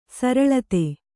♪ saraḷate